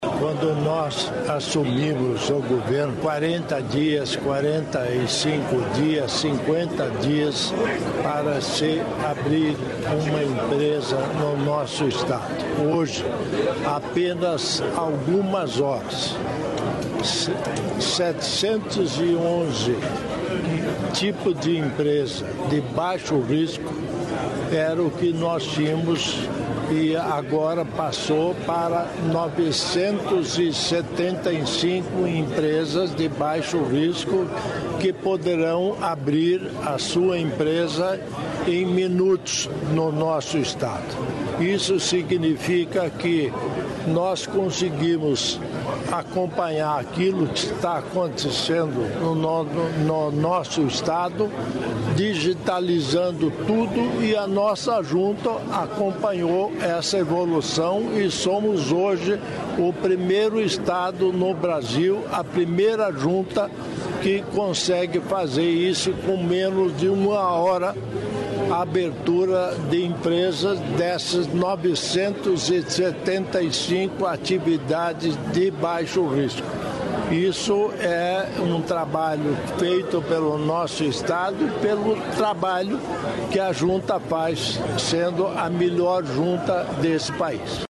Sonora do governador em exercício Darci Piana sobre lista de atividades com dispensa de licenciamentos